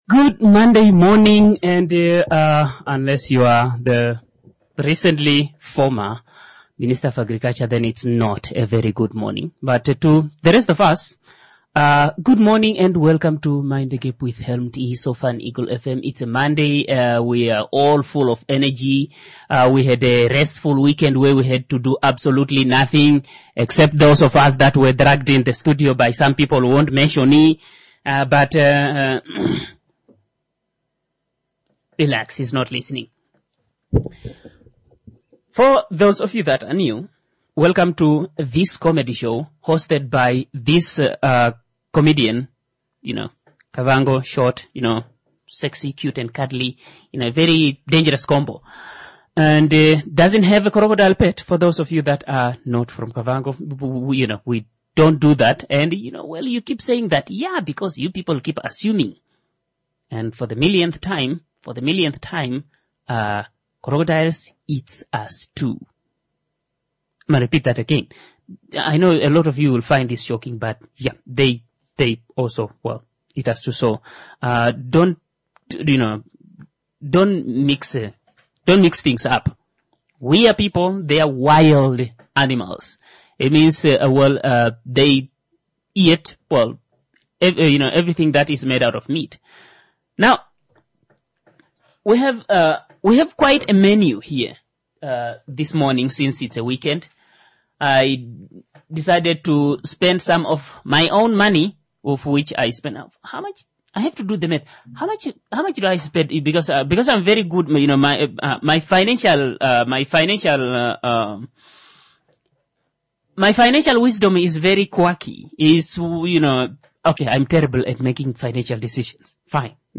⚠ This is a COMEDY show.